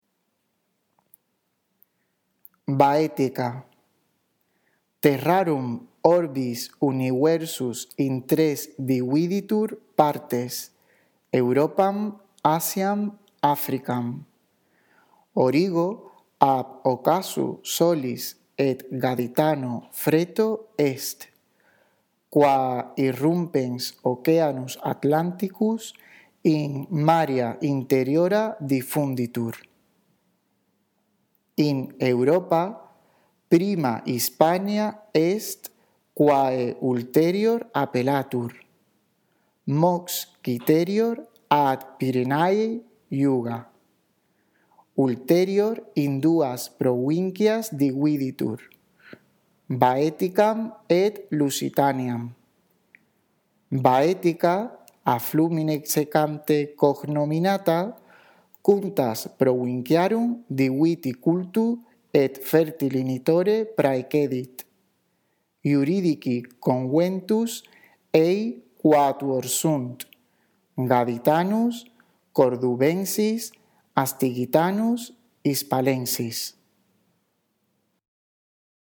Tienes una lectura justo debajo del texto por si la necesitas.
Después de escuchar con atención nuestra lectura del texto latino, lee tú despacio procurando pronunciar correctamente cada palabra y entonando cada una de las oraciones.